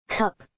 英 [kʌp]
英式发音